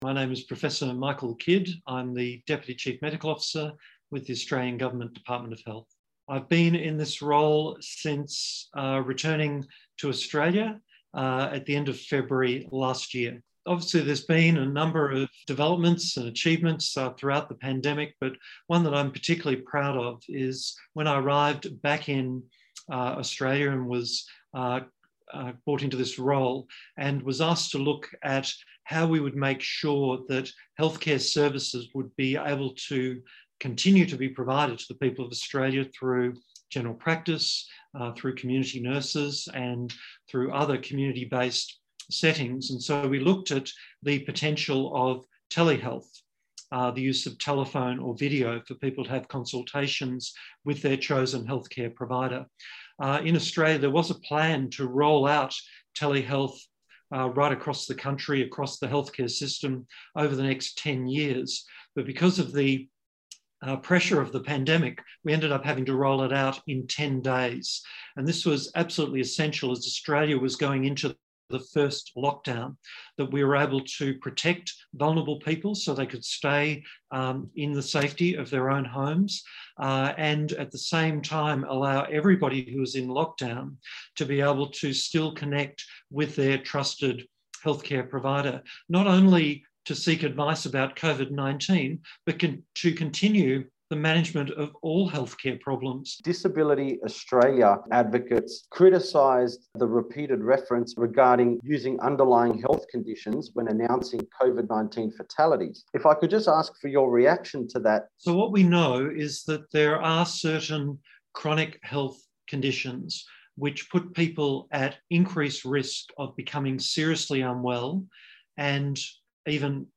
Interviu cu Michael Kidd, adjunctul Ofiterului medical sef din Australia.